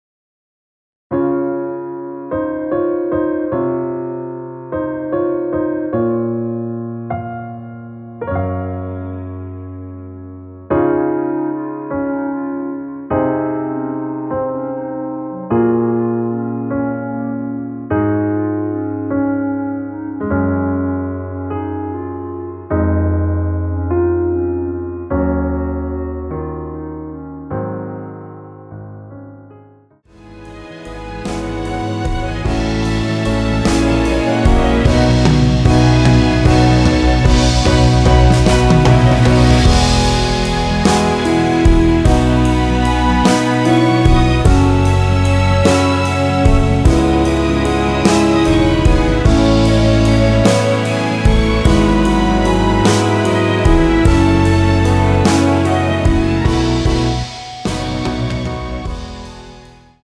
원키에서(-4)내린 멜로디 포함된 MR입니다.
Db
앞부분30초, 뒷부분30초씩 편집해서 올려 드리고 있습니다.